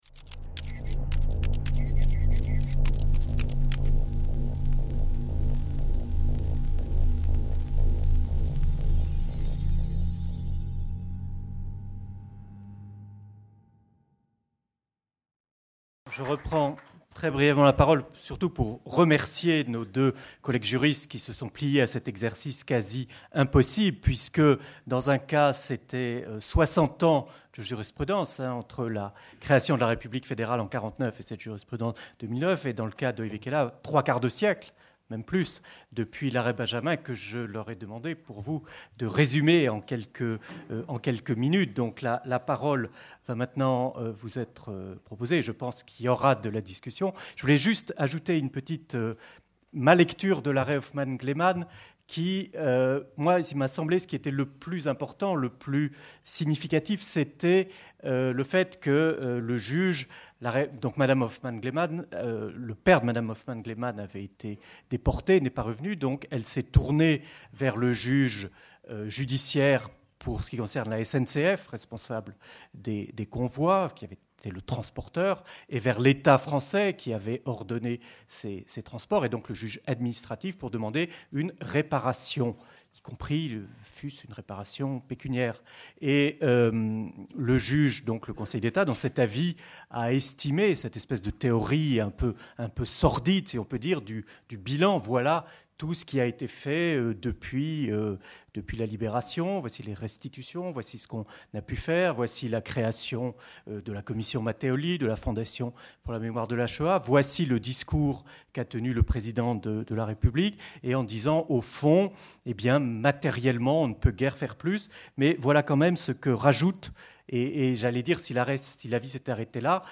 Retours sur « l’affaire Dieudonné » 1ère partie - intervenants 2ème partie - questions du public enregistré le mercredi 29 janvier 2014 à l'amphithéâtre de l'EHESS. Débat organisé par les Cercles de formation de l’EHESS et le Groupe d’études sur les historiographies modernes (GEHM), équipe du CRH.